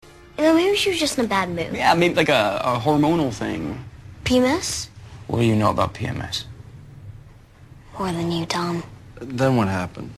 아까 말씀드린 예는 문장을 시작하는 부분을 불분명하게 발음하는 경향 때문에 듣기가 어려웠구요